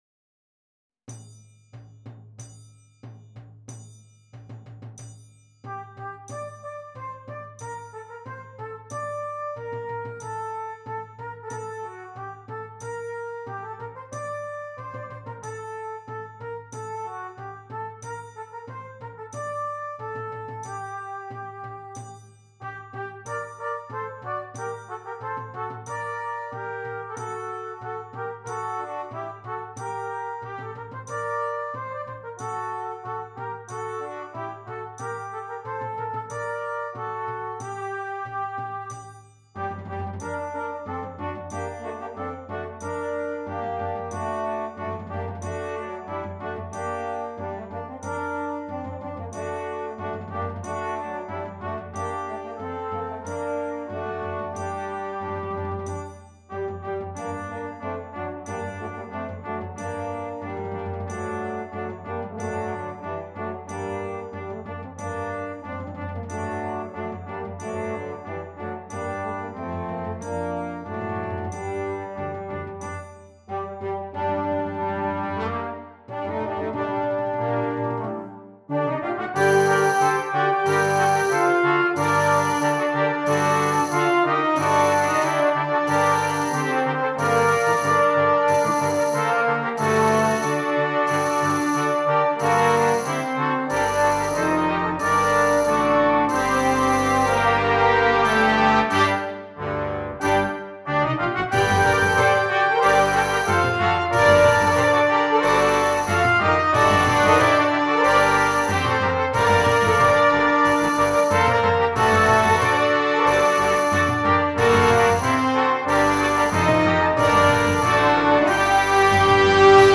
Christmas
Brass Choir (4.2.3.1.2.perc)
Traditional